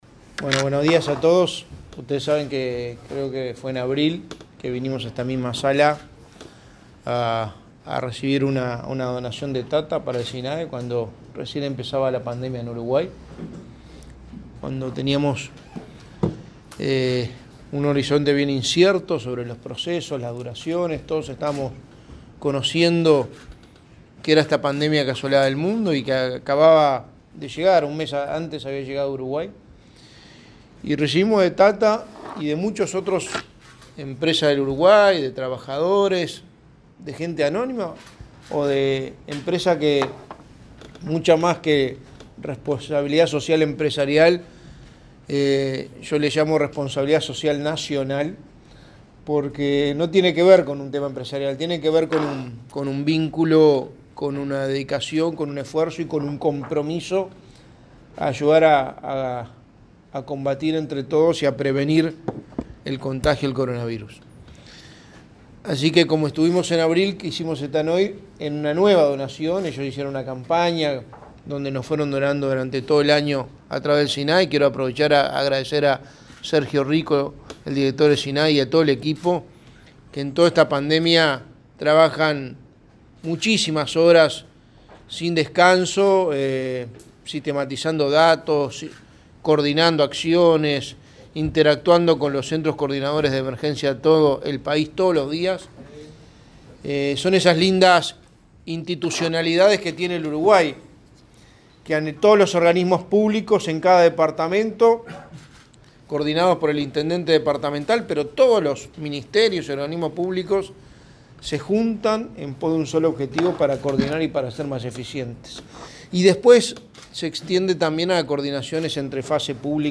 Declaraciones del secretario de Presidencia, Álvaro Delgado
El Sistema Nacional de Emergencias recibió, este 20 de enero, 200.000 tapabocas para distribuir en todo el país. Delgado participó en el evento.